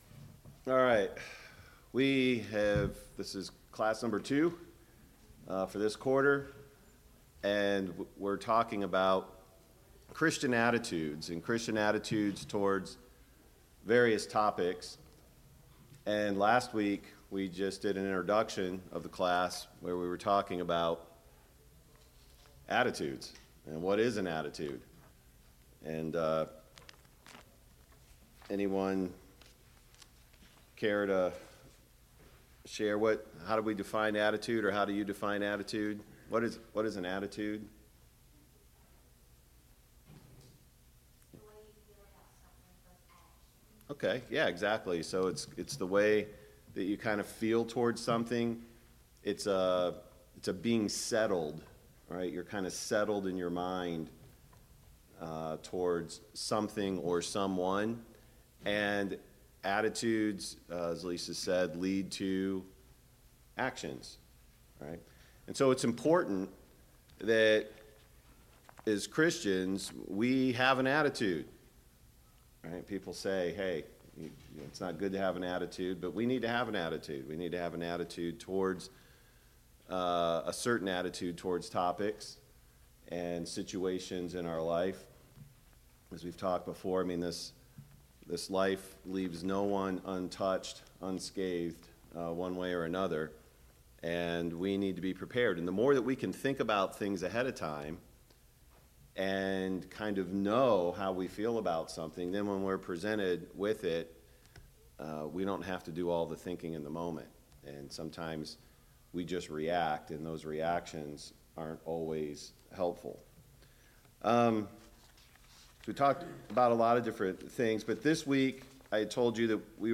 Sunday Morning Bible Class « 79.